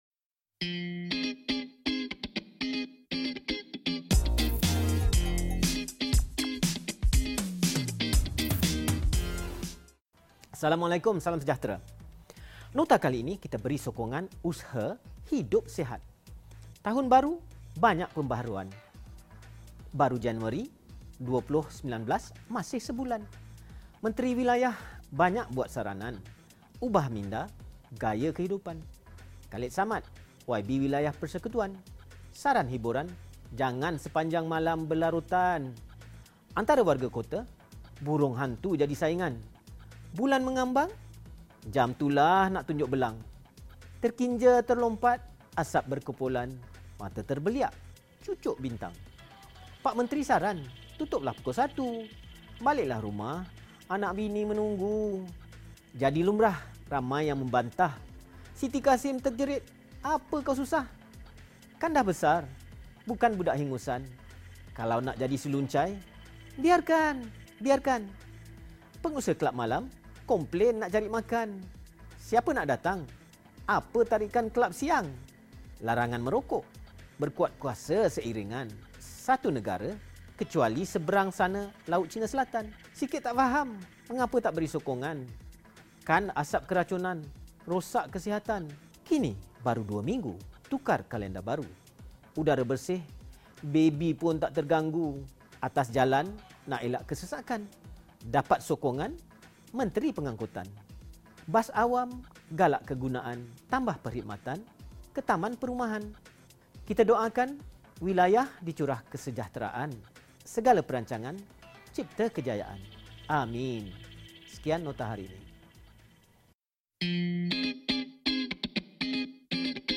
Ulasan berita